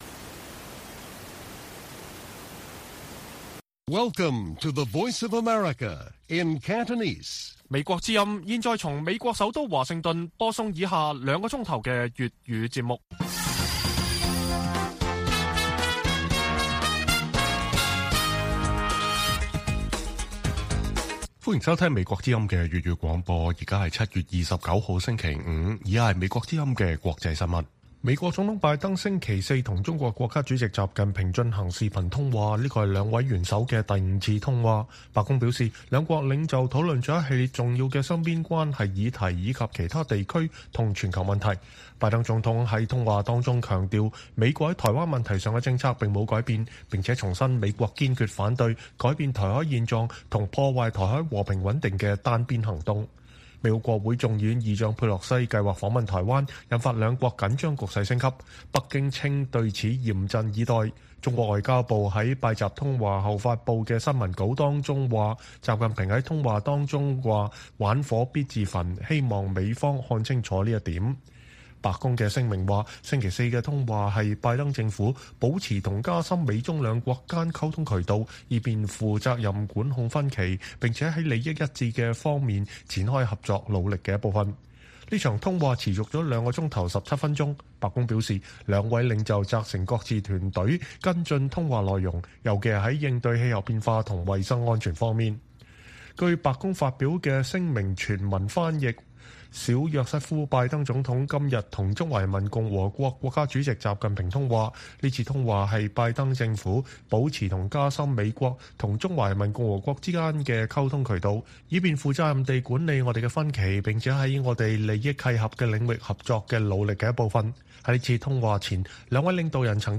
粵語新聞 晚上9-10點: 拜登對習近平強調美國反對單方面改變現狀和破壞台海和平的企圖